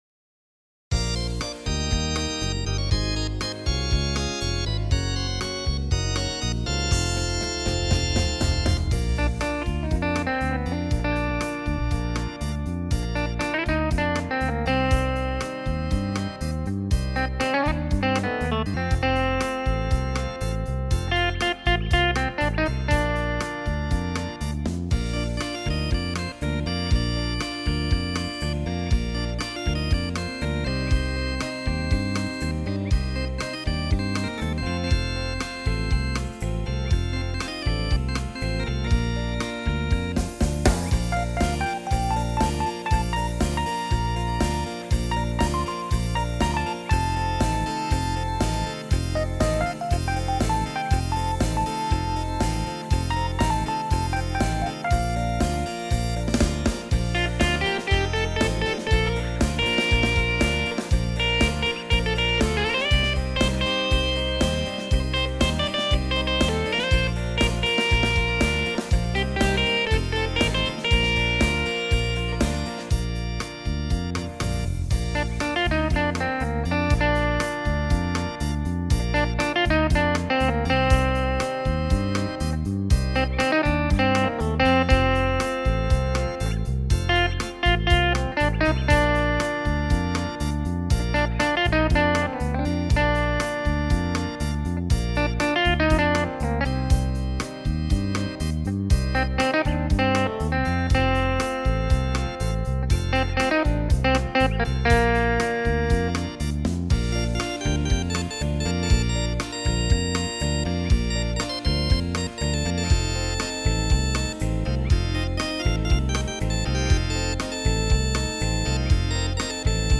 60s underground Tempo 120